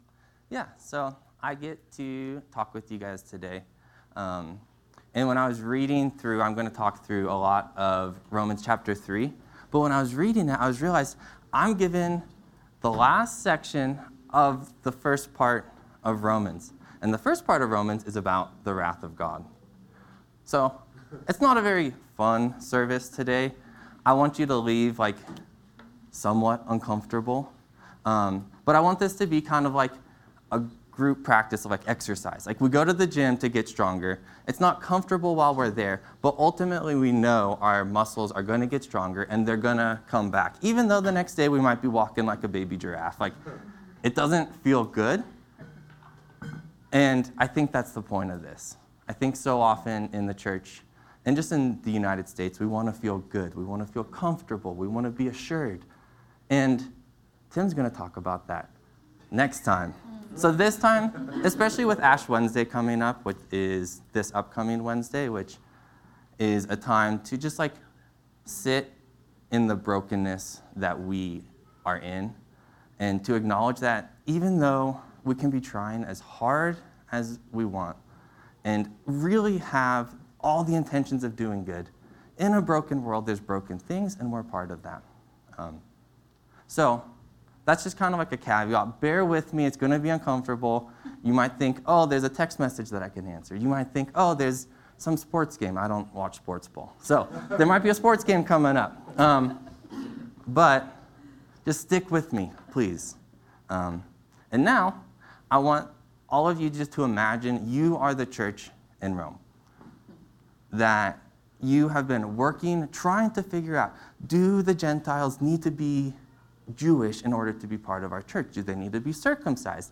Sermons from Sunday Gatherings at Sola Church